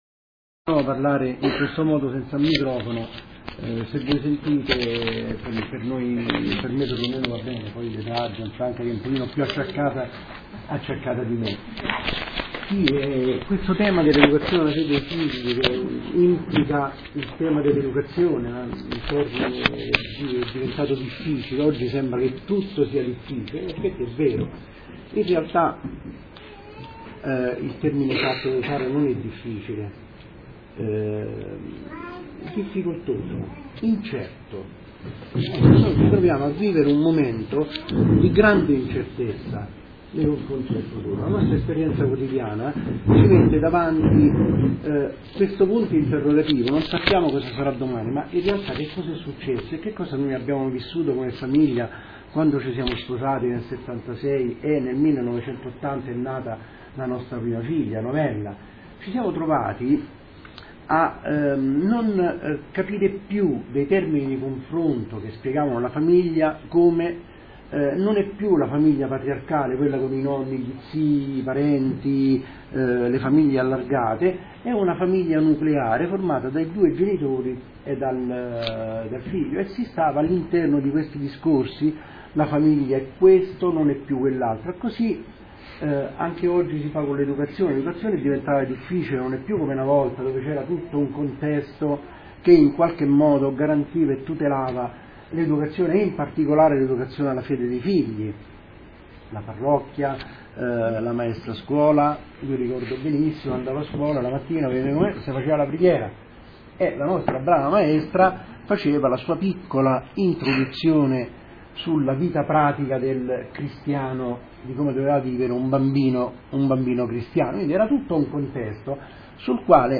Una bella testimonianza sul tema dell'educazione alla fede dei figli.